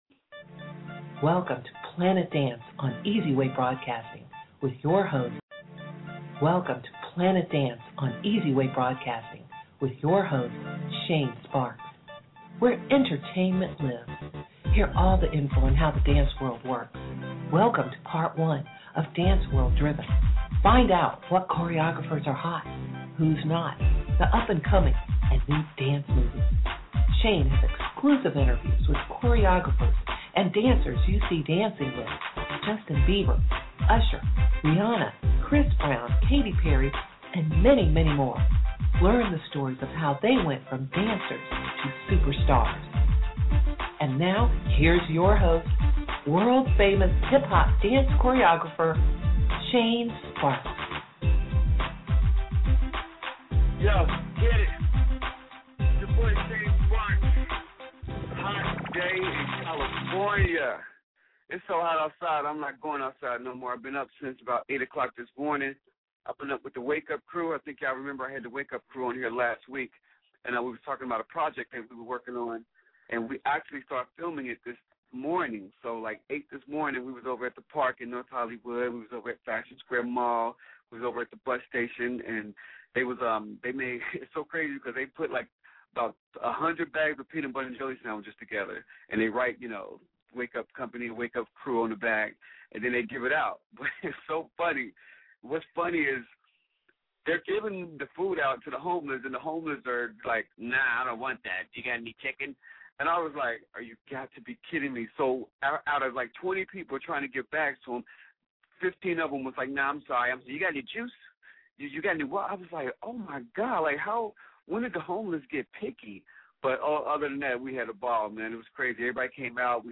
Hip Hop Music